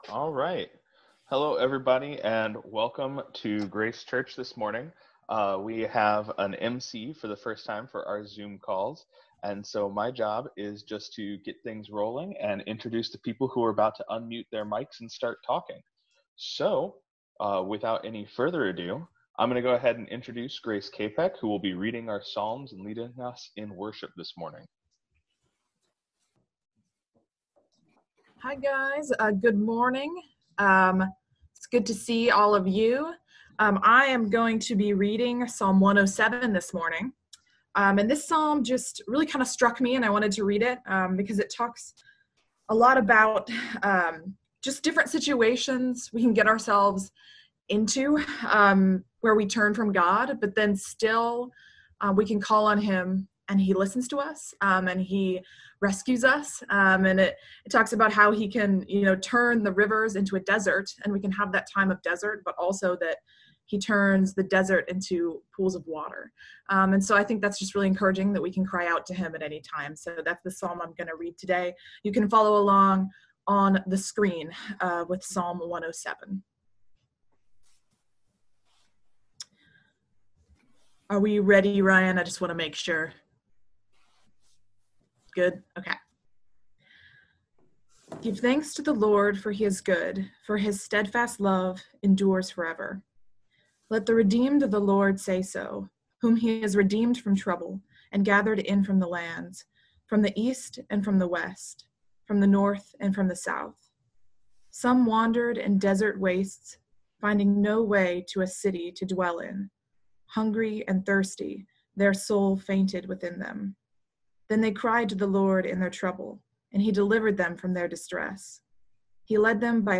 Apr 25, 2020 War Against Worldliness (04.19.2020) MP3 PDF SUBSCRIBE on iTunes(Podcast) Notes Discussion Sermons in this Series Your desires reveal what you truly treasure in your heart. If there is anything in your heart other than Jesus Christ, you face the idolatry of worldliness.